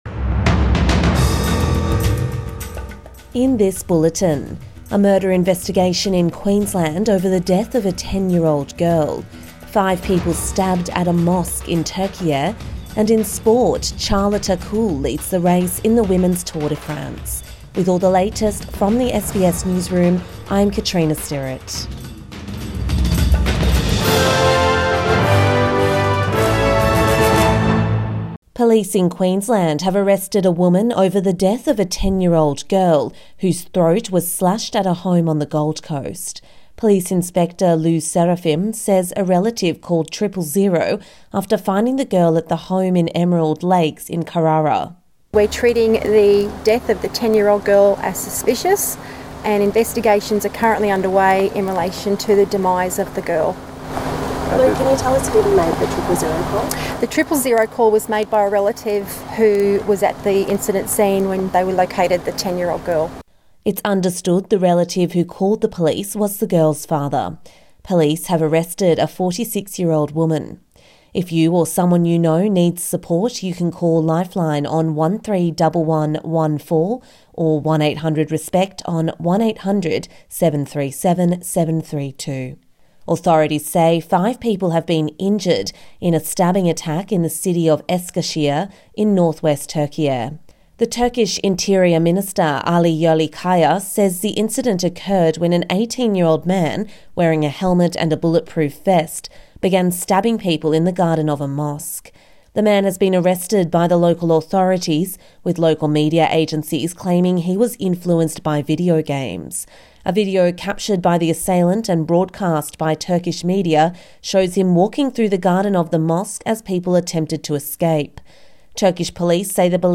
Morning News Bulletin 14 August 2024